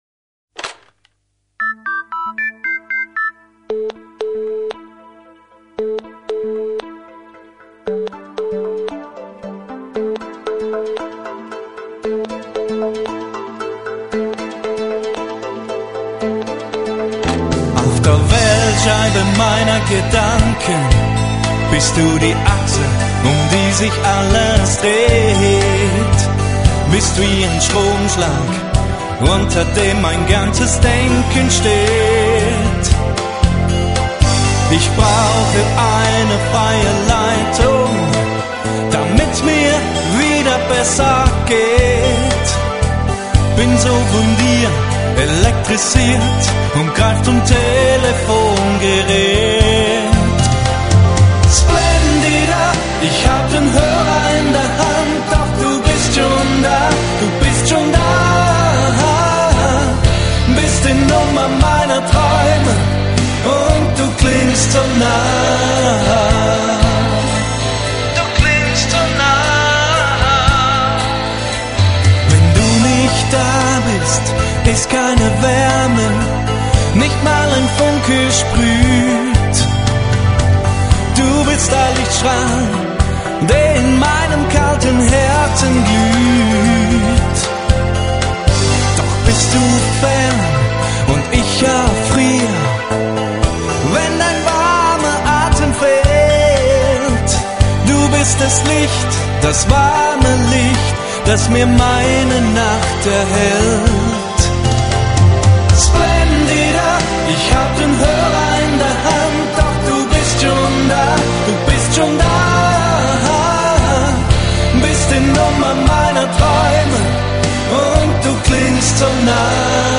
Schlager-Hymne
Studioproduktion 2001, Vocals & Saxofon